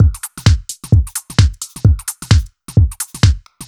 Index of /musicradar/uk-garage-samples/130bpm Lines n Loops/Beats
GA_BeatnPercE130-03.wav